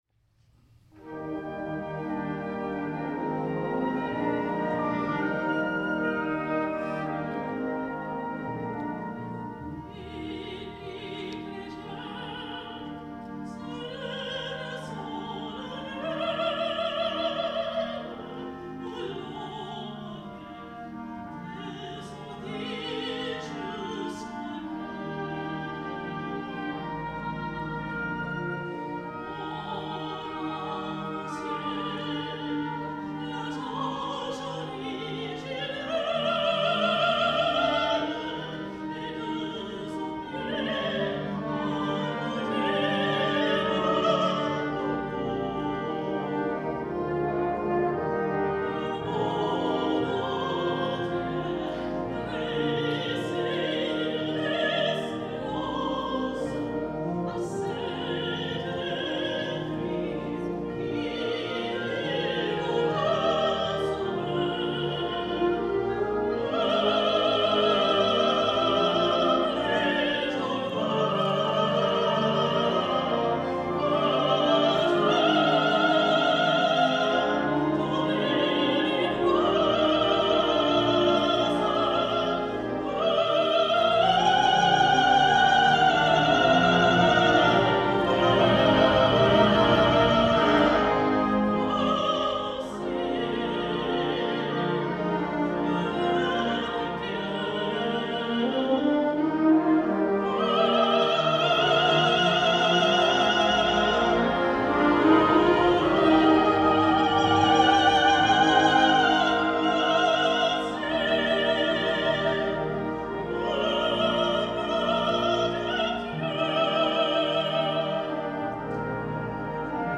ソプラノまたは管楽器のソロをフィーチャーしたクリスマスキャロルのアレンジ。
編成：吹奏楽
Orchestra Bells
Chimes
Solo Soprano, Solo B♭ Instrument